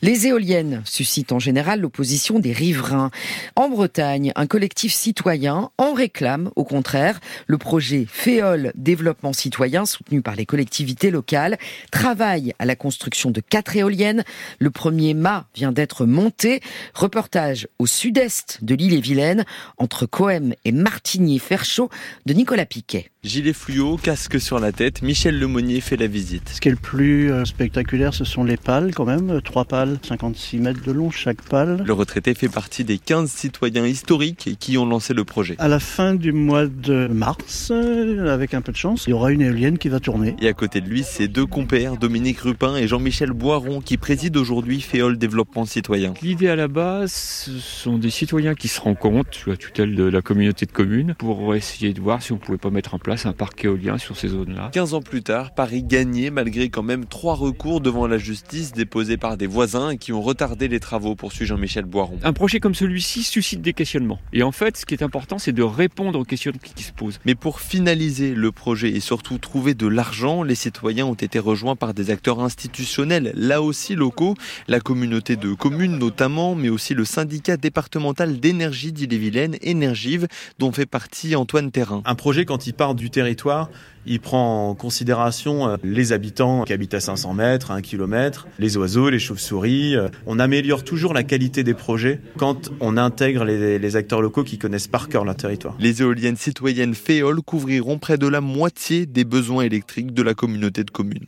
La matinale de France Inter est allée rendre visite aux acteurs citoyens du parc éolien de Féeole en Ille-et-Vilaine, co-financé par Énergie Partagée.